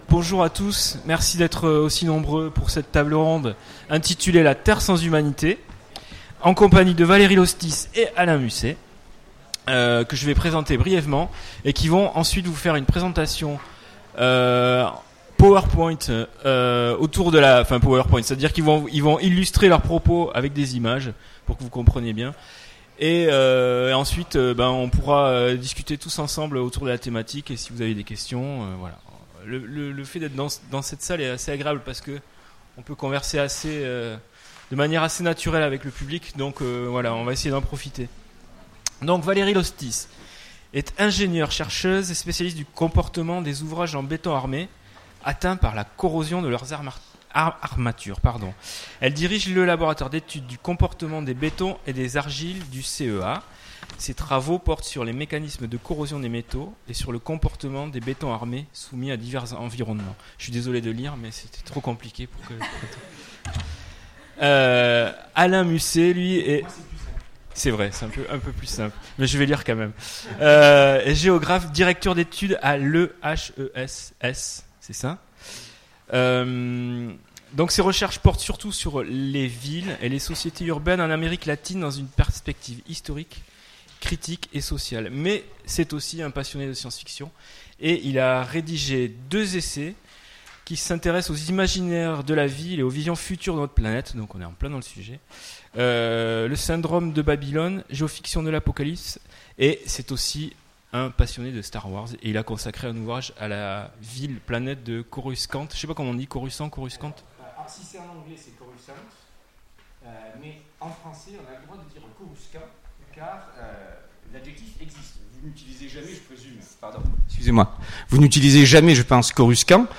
Utopiales 2017 : Conférence La terre sans humanité ?